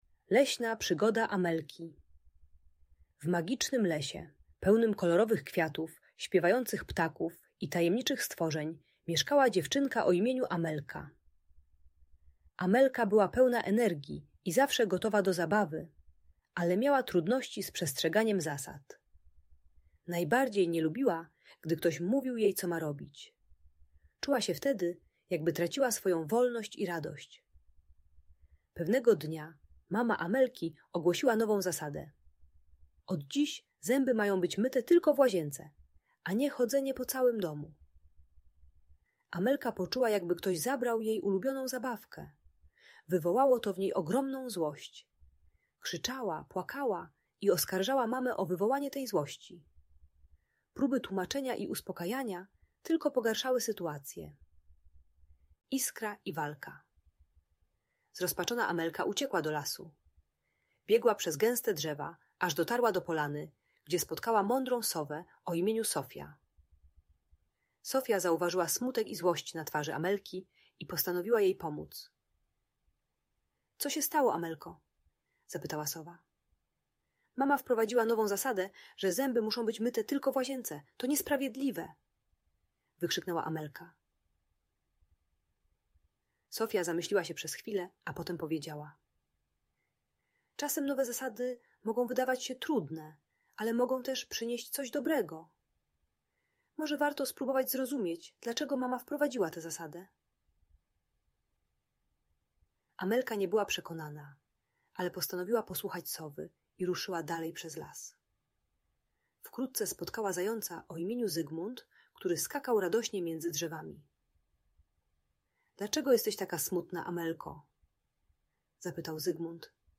Leśna Przygoda Amelki - Urocza i pouczająca story - Audiobajka